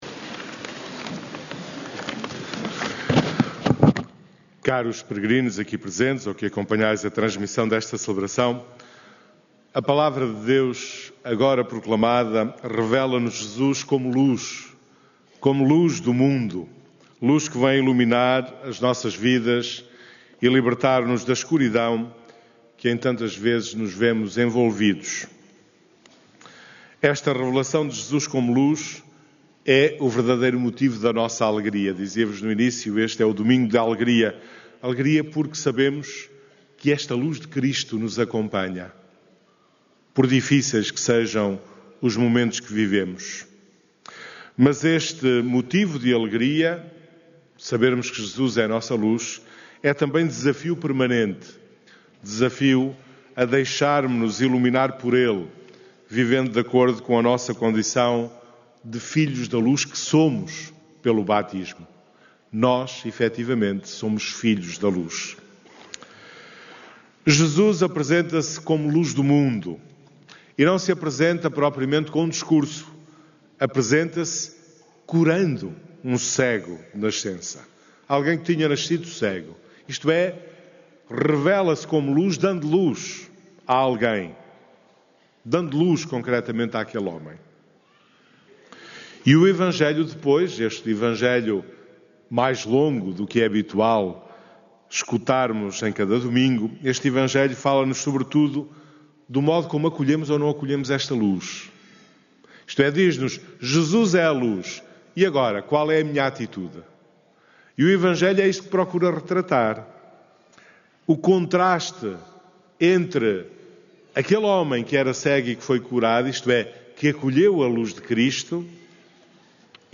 Os peregrinos que participaram na missa deste IV Domingo da Quaresma, na Basílica da Santíssima Trindade, esta manhã, foram exortados a viver como "filhos da Luz" e a agir de forma coerente com a sua condição de batizados.